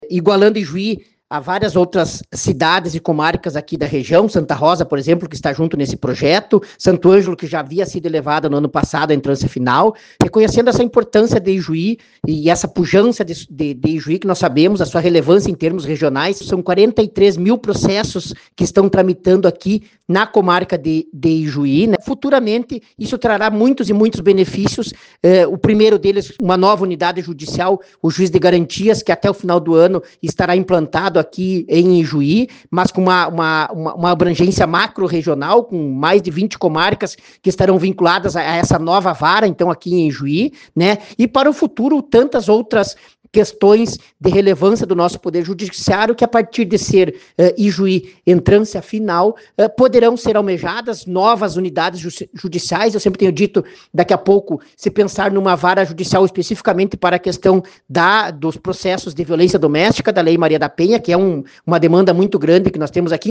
O Juiz titular da 1ª Vara Criminal da Comarca de Ijuí, Eduardo Giovelli, ressalta que a passagem da unidade local da Justiça estadual para entrância final reconhece a importância do município e vai se traduzir em novos serviços para a comunidade. (Abaixo, sonora de Giovelli)